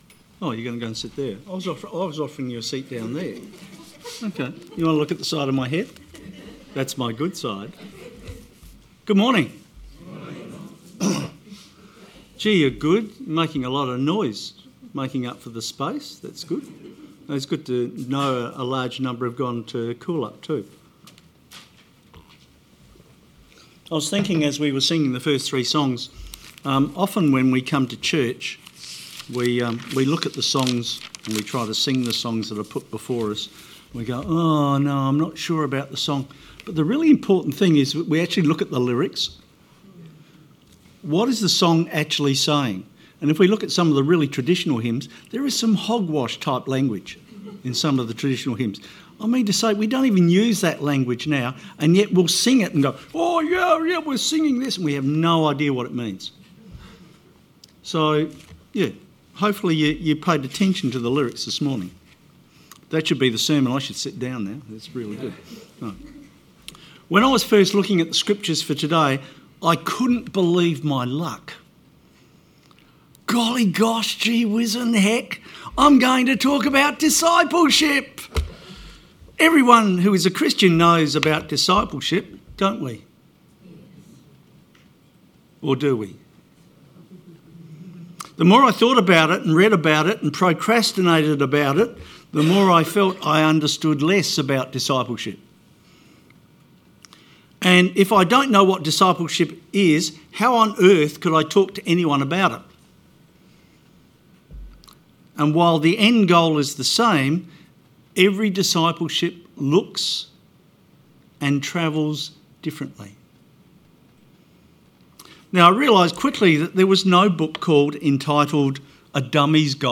Sermon 29th June 2025